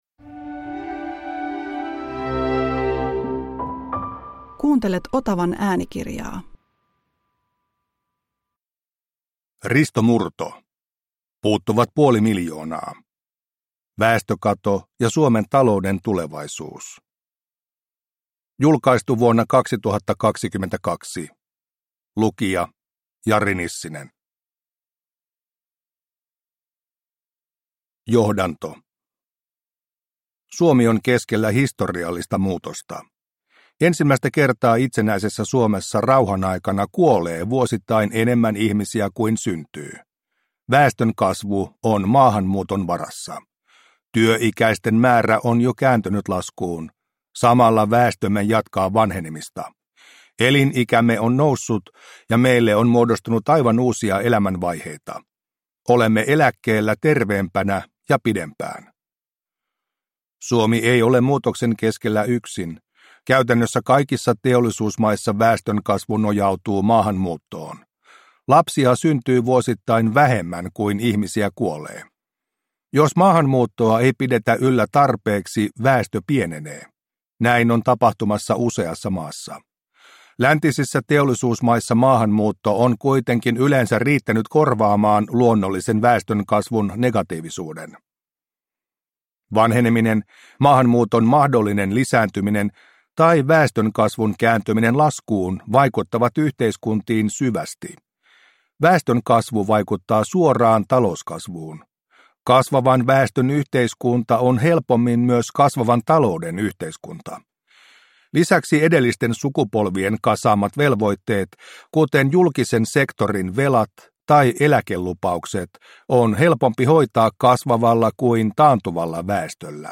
Puuttuvat puoli miljoonaa – Ljudbok – Laddas ner